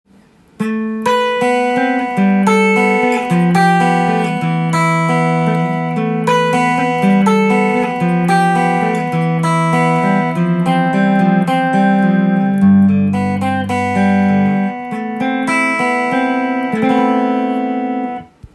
試しに弾いてみました
ハリがあるクリーントーンです。
音の方も高級感満載ですね。